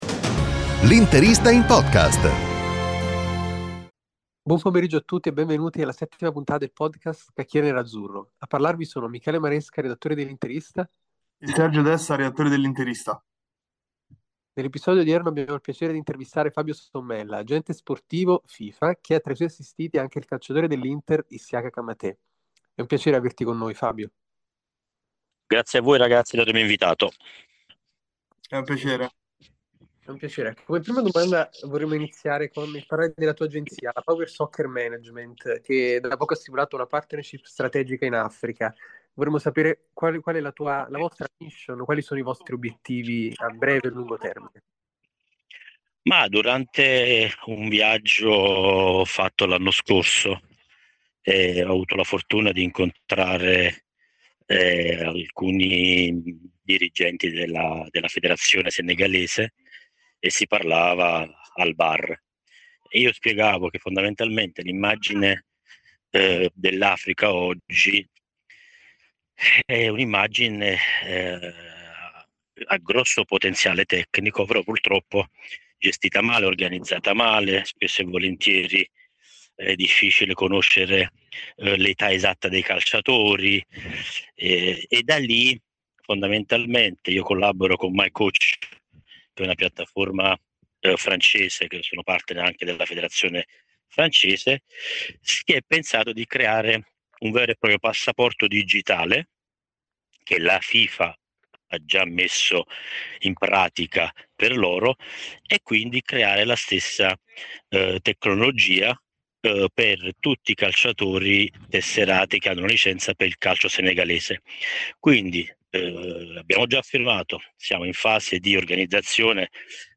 Nell'episodio odierno abbiamo il piacere di intervistare